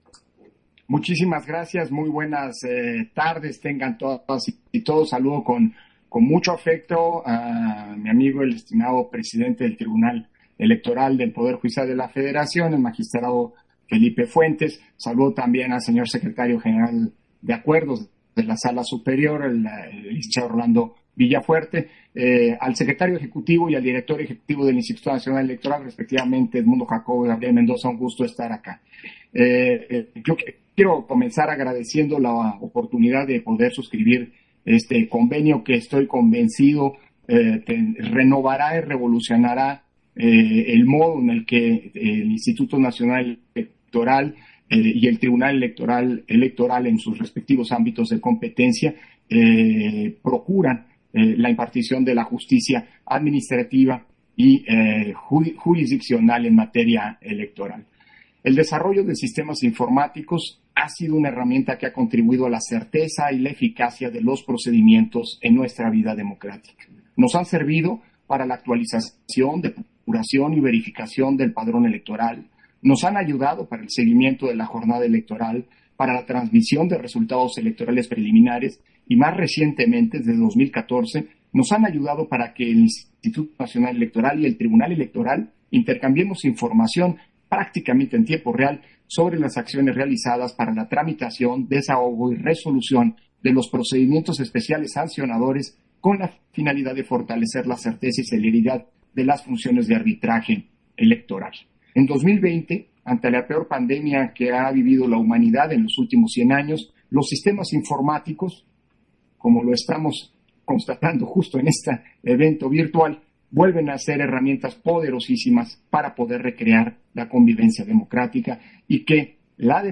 Intervención de Lorenzo Córdova, en la firma de Convenio de Colaboración INE-TEPJF, para la implementación del Sistema Juicio en Línea, en los medios de impugnación en materia electoral